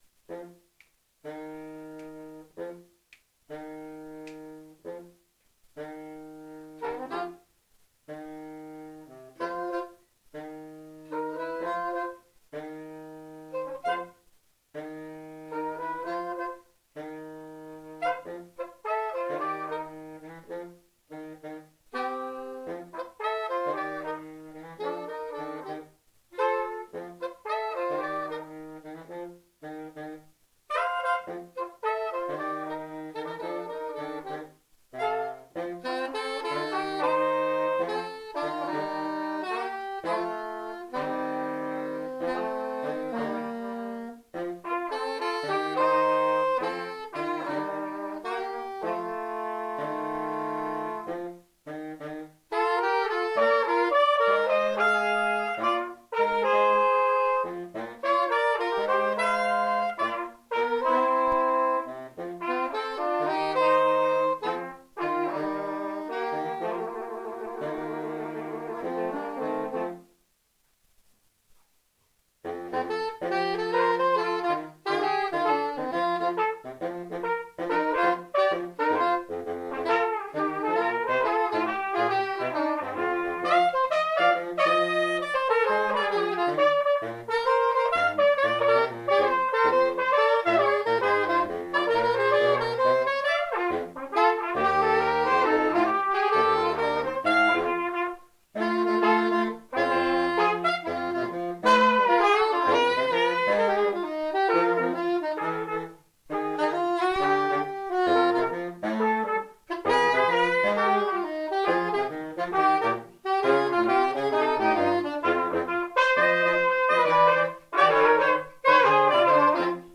· Genre (Stil): Jazz